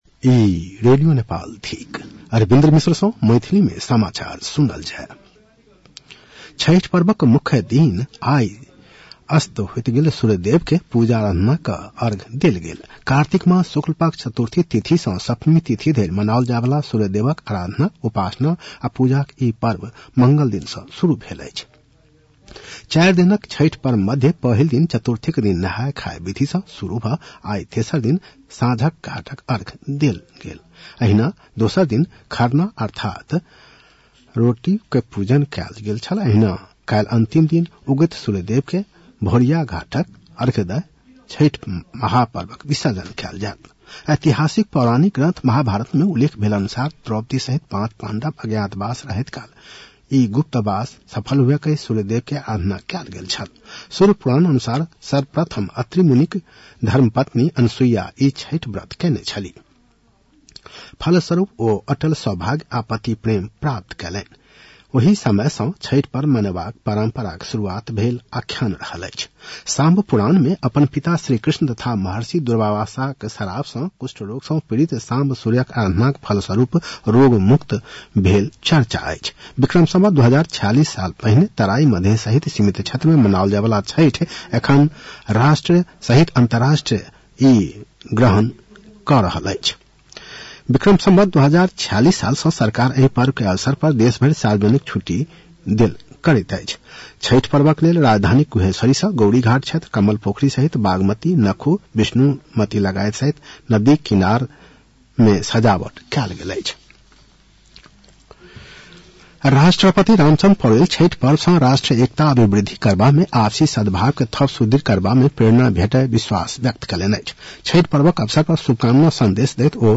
मैथिली भाषामा समाचार : २३ कार्तिक , २०८१